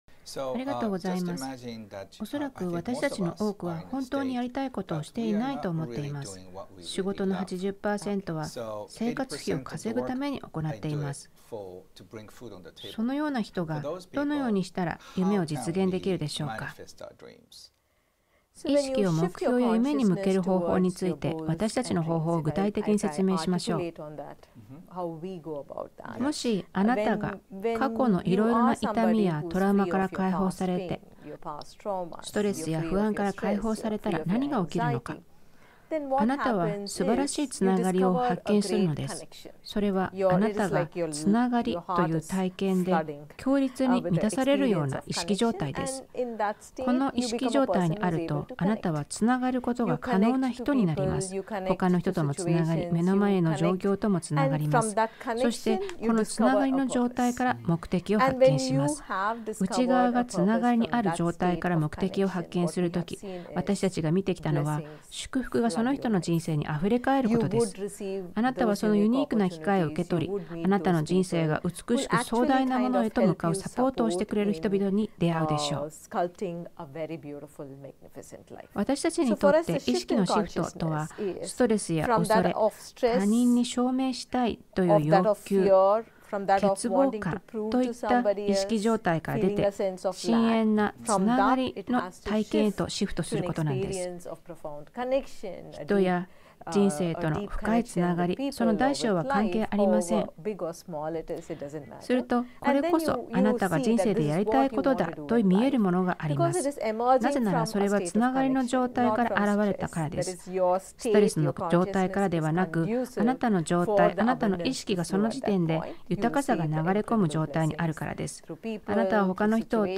人生の目的について、本田健がインドの聖者 プリタジ師に伺った内容をお届けします。